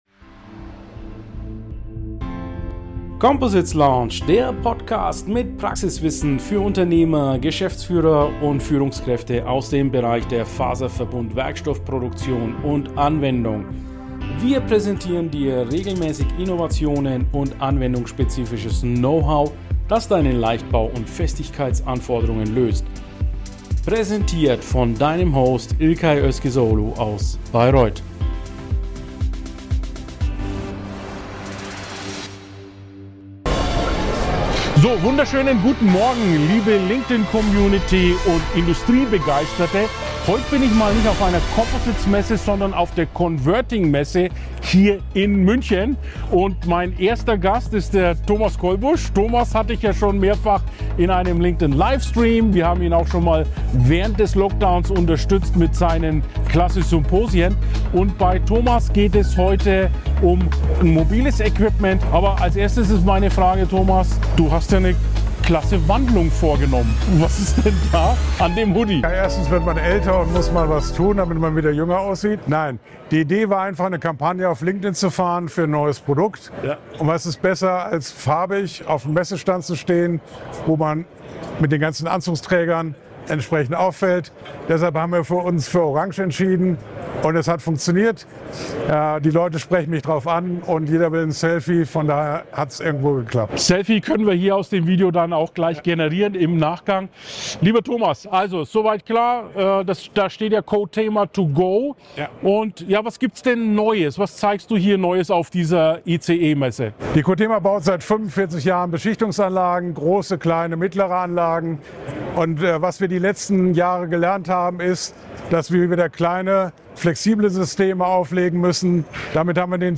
#167 Innovation, Technologie und Nachhaltigkeit auf der ICE Messe in München mit Coatema